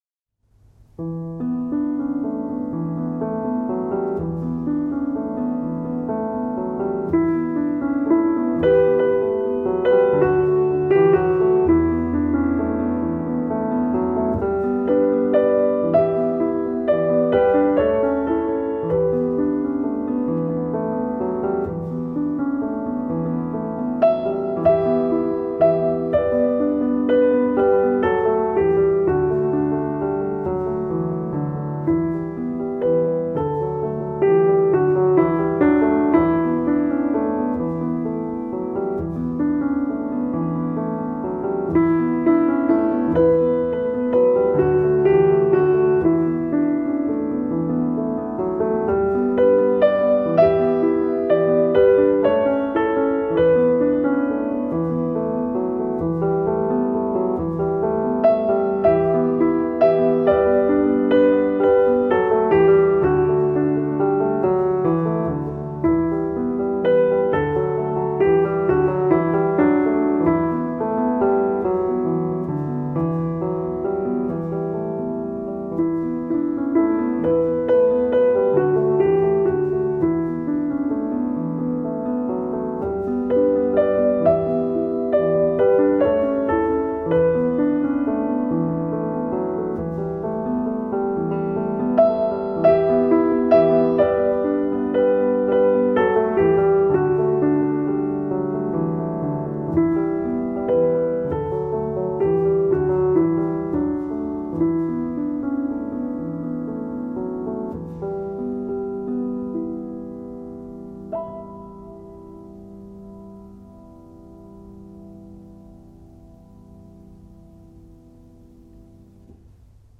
• Категория:Музыка для сна